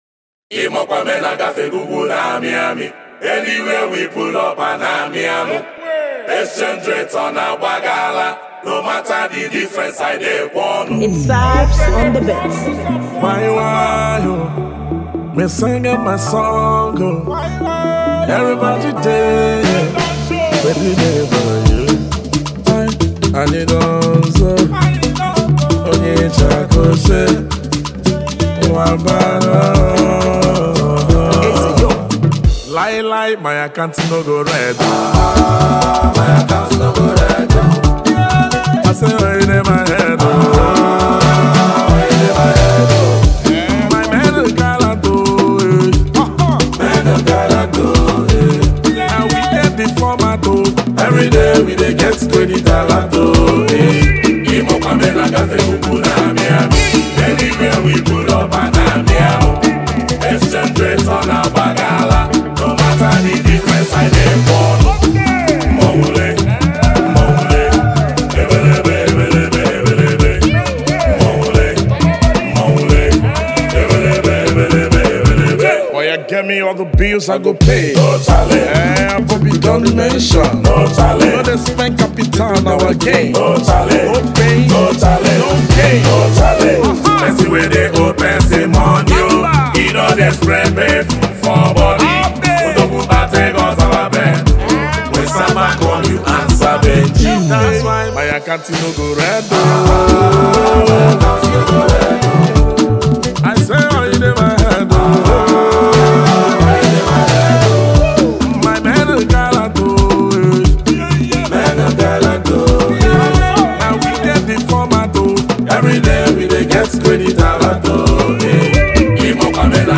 Igbo Music and Highlife